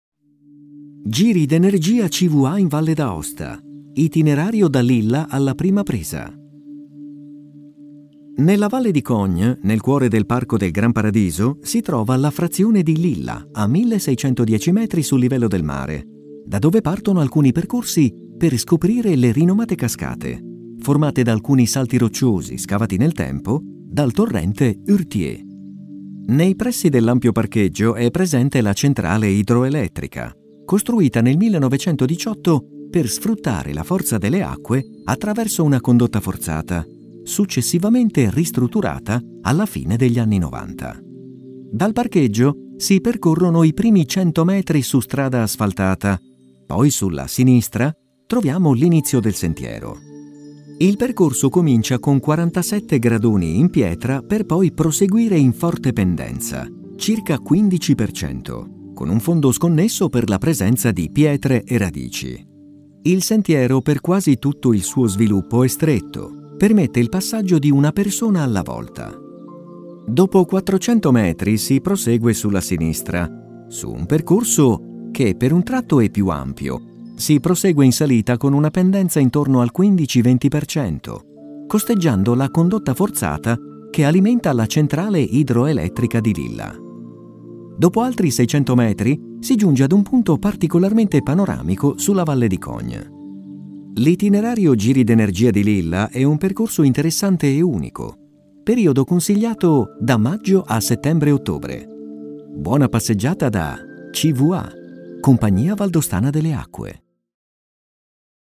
Audioguida Video LIS